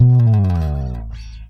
SLIDEFING.wav